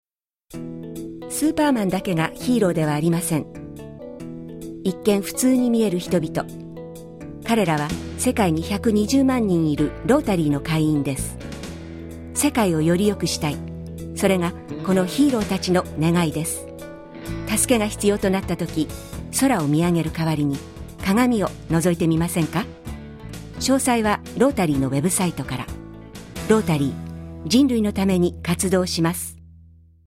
「人類のために活動します」のラジオ用公共奉仕広告（その2）